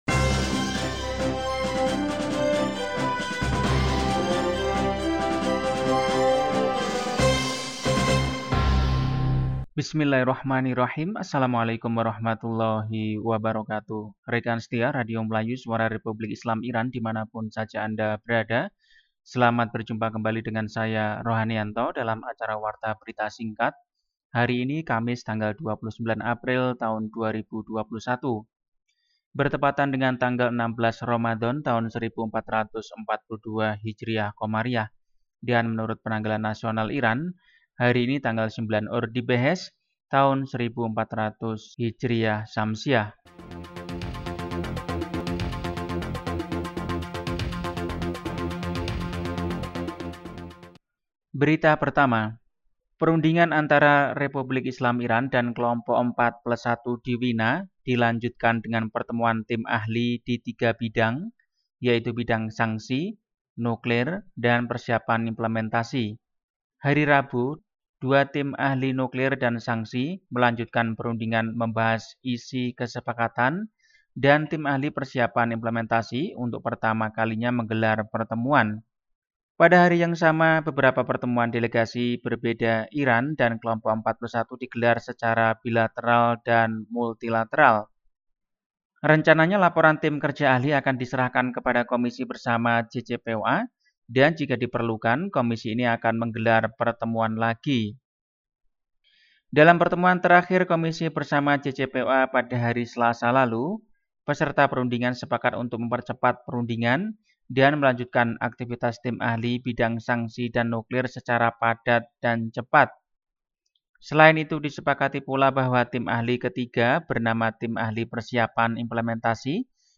Warta berita hari ini, Kamis, 29 April 2021.